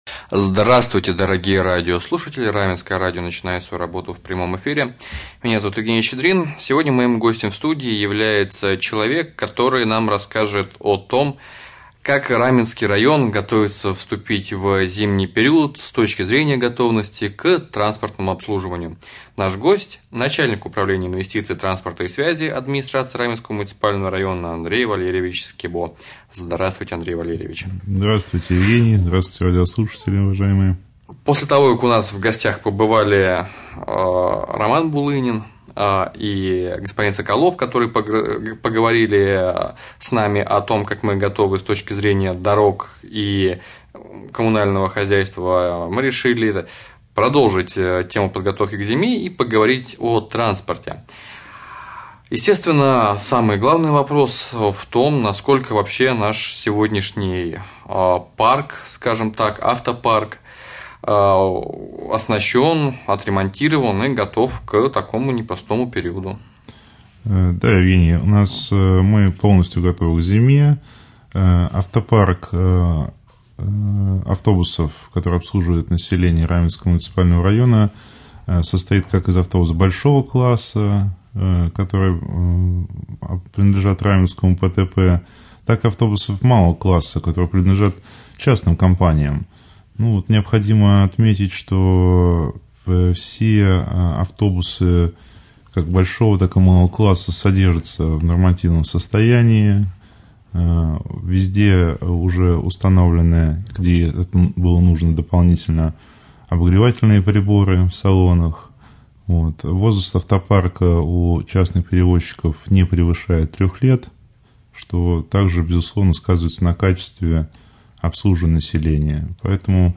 В студии начальник Управления инвестиций, транспорта и связи Андрей Скибо
Прямой эфир с начальником Управления инвестиций, транспорта и связи администрации Раменского района Андреем Валерьевичем Скибо.